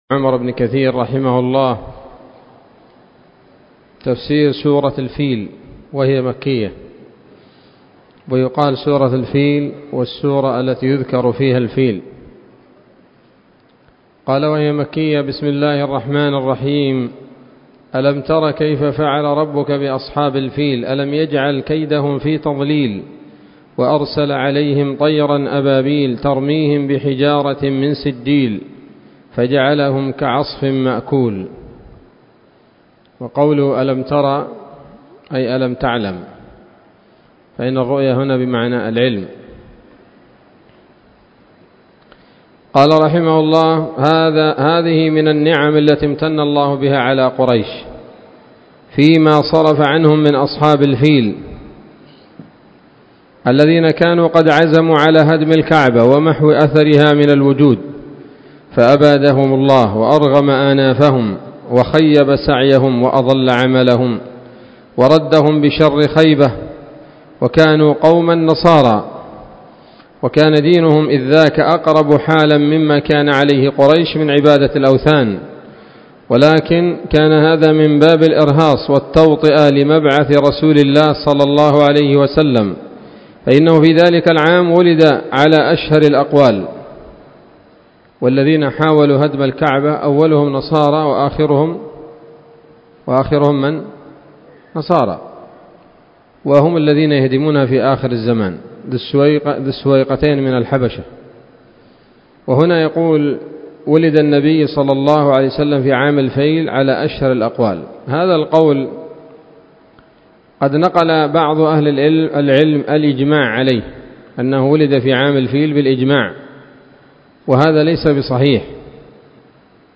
الدرس الأول من سورة الفيل من تفسير ابن كثير رحمه الله تعالى